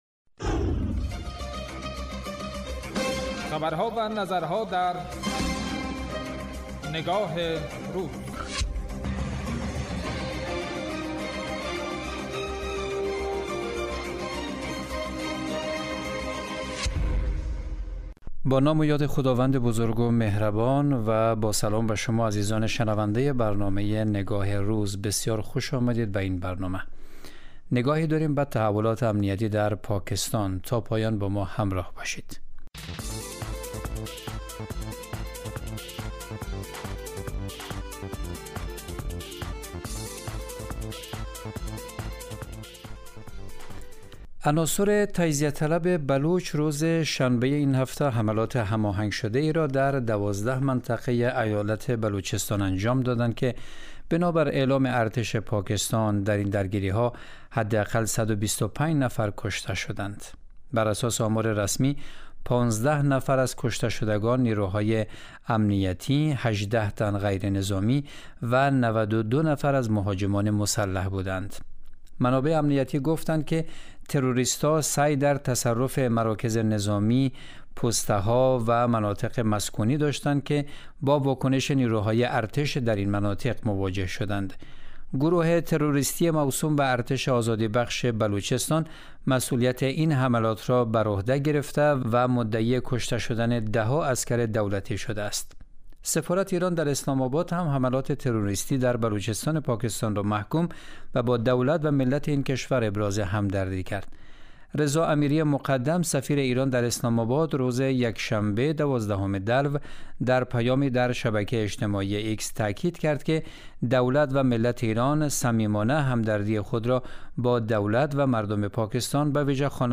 اطلاع رسانی و تحلیل و تبیین رویدادها و مناسبت‌های مهم، رویکرد اصلی برنامه نگاه روز است که روزهای شنبه تا پنج‌شنبه ساعت 13:00 به مدت 10 دقیقه پخش می‌شود.